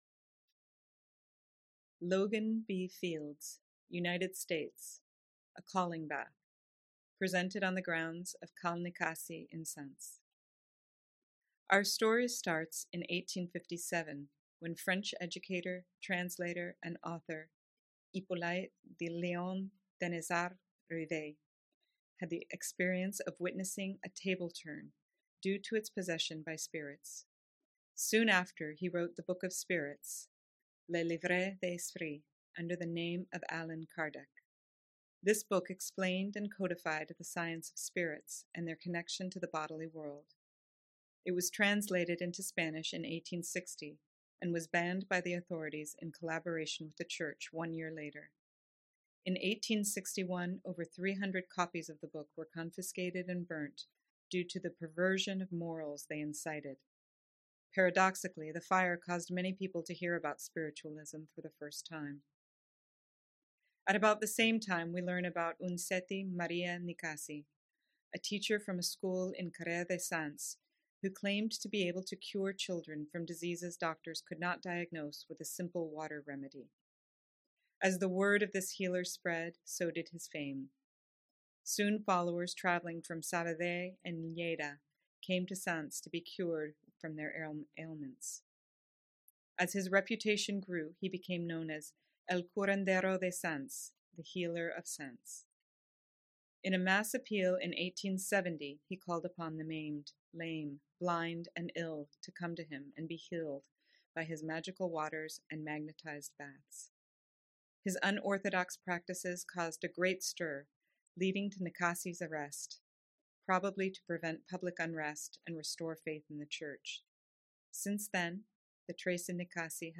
artist